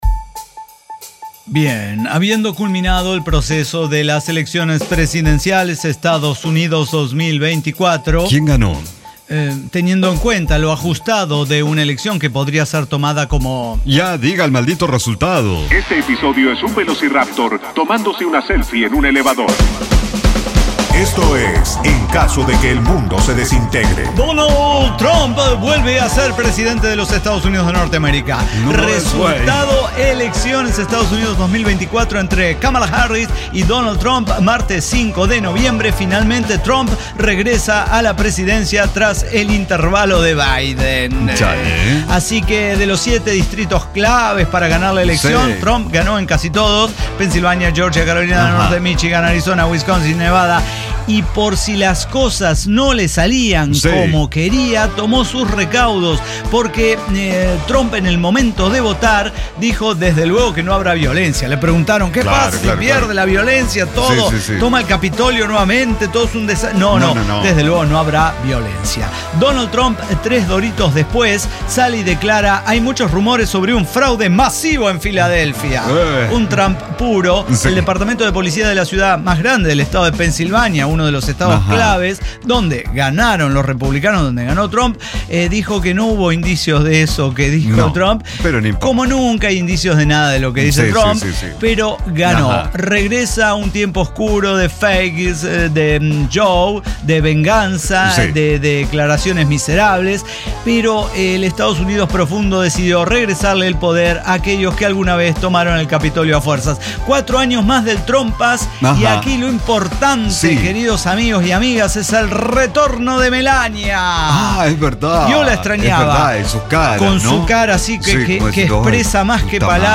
El Cyber Talk Show
Diseño, guionado, música, edición y voces son de nuestra completa intervención humana.